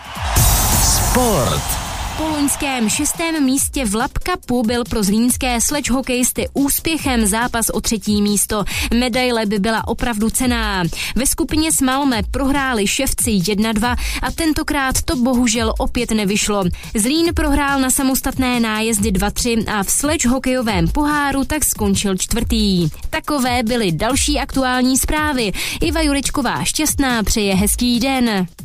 Reportáž z Lapp Cupu Zlín 2015.